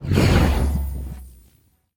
Minecraft Version Minecraft Version snapshot Latest Release | Latest Snapshot snapshot / assets / minecraft / sounds / mob / evocation_illager / cast1.ogg Compare With Compare With Latest Release | Latest Snapshot